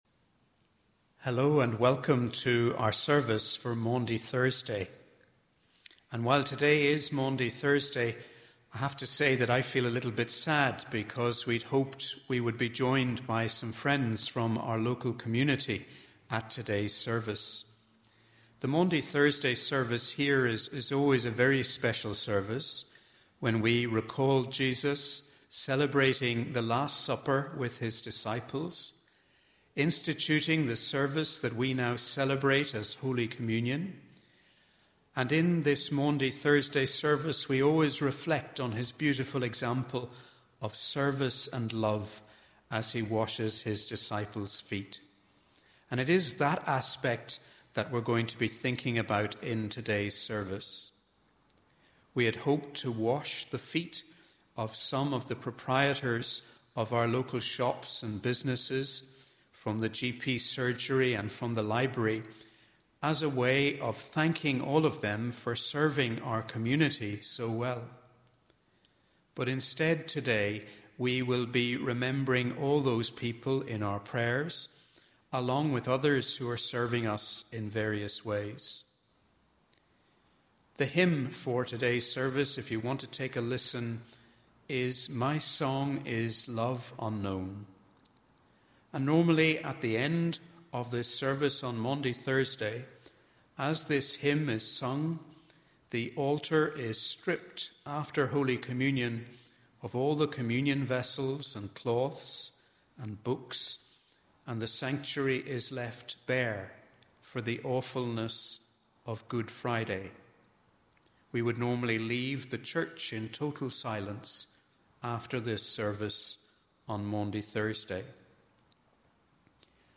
Both the audio and the video finish with “My Song is Love Unknown”.
Holy-Week-Service_Thursday_FINAL-Audio.mp3